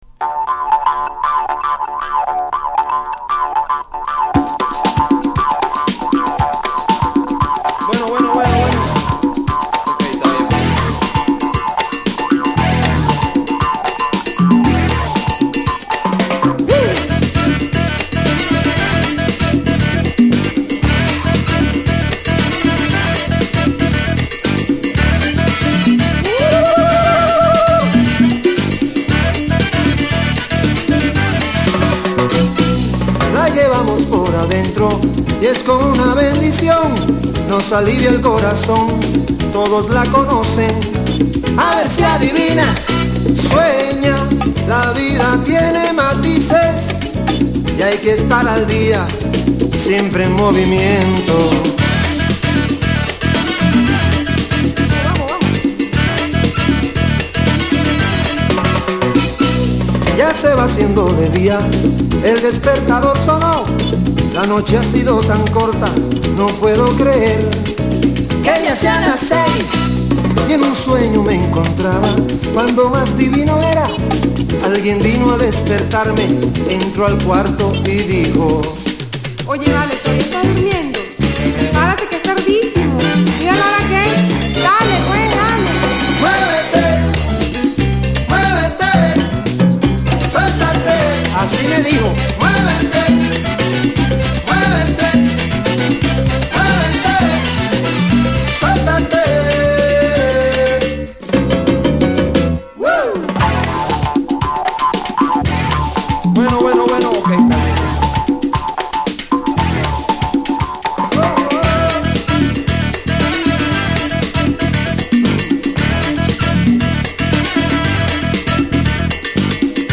Latin venezuela
ソリッドなホーンを取り入れたヘヴィーなリフと、サルサ風味を絶妙に織り交ぜた展開は否が応でも踊らずにはいられません。